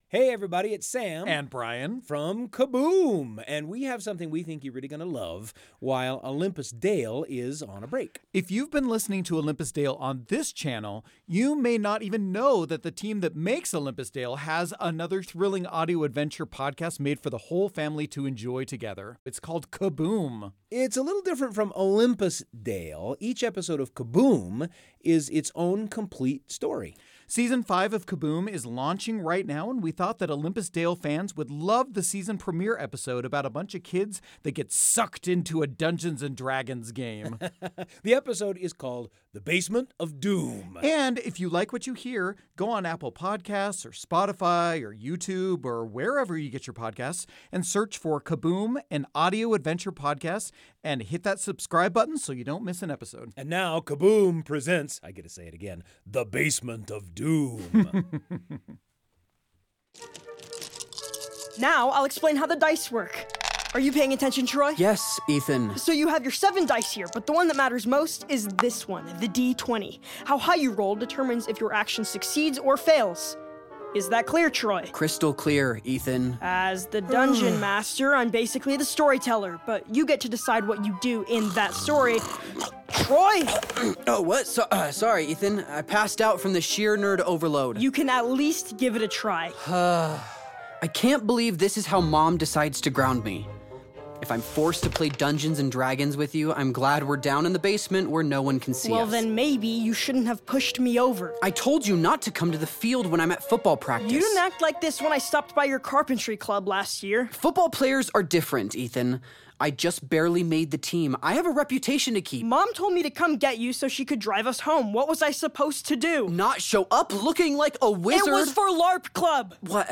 Olympus Dale Introducing: Kaboom Play episode February 6 19 mins Bookmarks Episode Description Fans of Olympus Dale will love Kaboom, another thrilling audio adventure podcast produced by the same team. Season 5 of Kaboom is launching now, and we wanted to share an episode called "The Basement of Doom" about a bunch of kids sucked into a Dungeons and Dragons game.